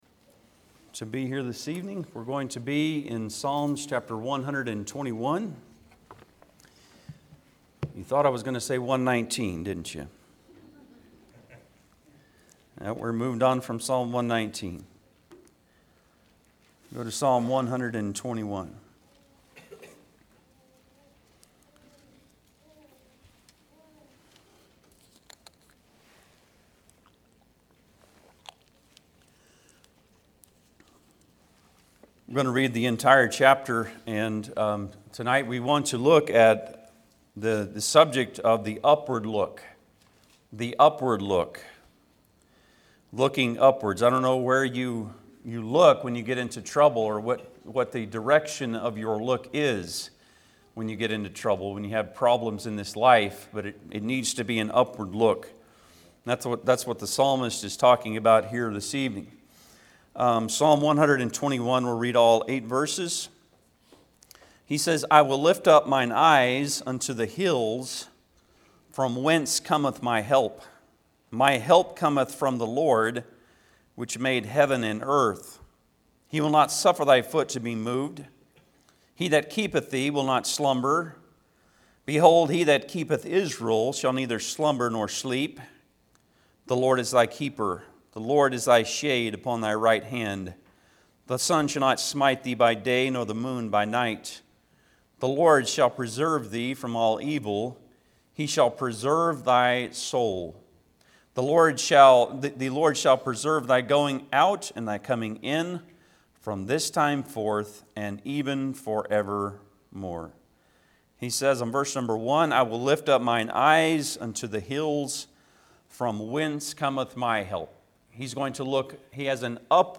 Bible Text: Psalms 121 | Preacher